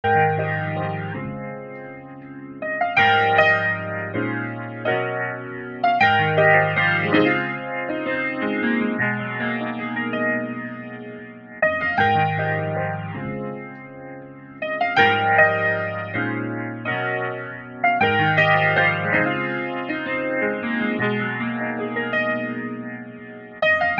悲伤的钢琴
标签： 111 bpm LoFi Loops Piano Loops 2.05 MB wav Key : Unknown FL Studio